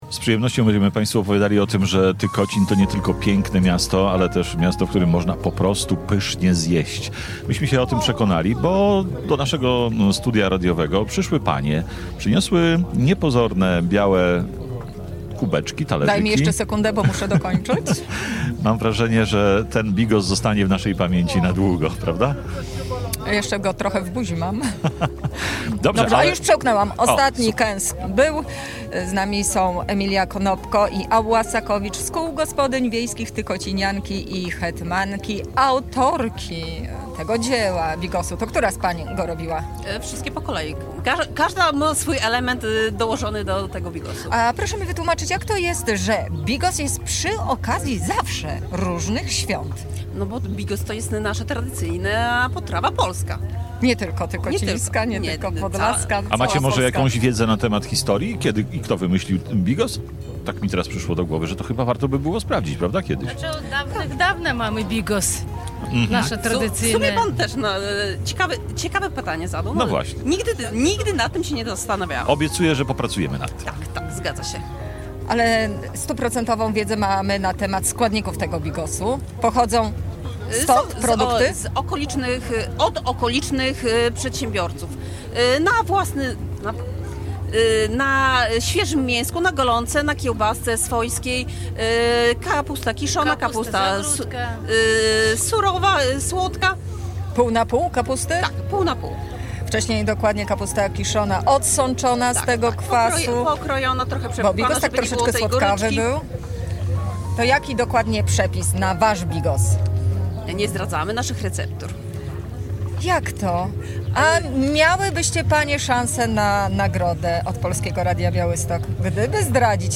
W sobotę, 3 maja właśnie z tego miasteczka program nadawało Polskie Radio Białystok.
Nasz program z Tykocina nadawaliśmy od 14:00 do 18.00. W naszym plenerowym studiu na Placu Czarnieckiego nasi słuchacze mogli podpatrzeć pracę dziennikarzy, ale także wziąć udział w wielu konkursach, które przygotowaliśmy.